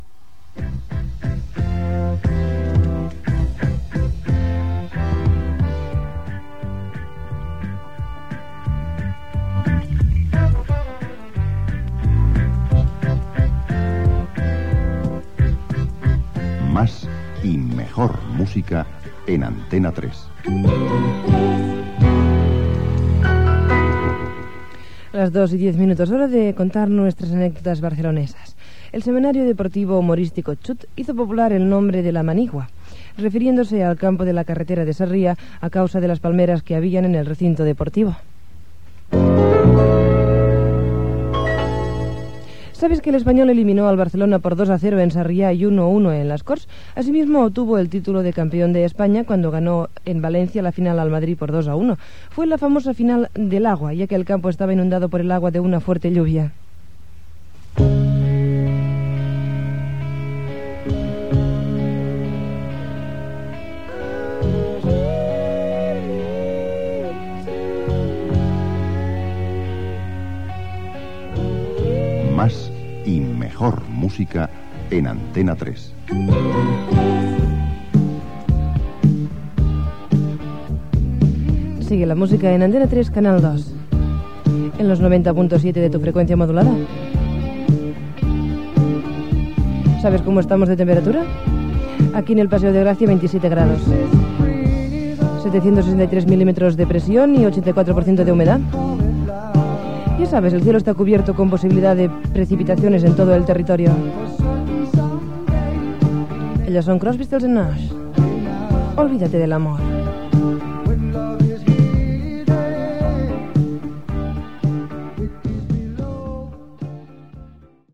Indicatiu, hora, anècdotes barcelonines, indicatiu, temperatura i tema musical.
Entreteniment
FM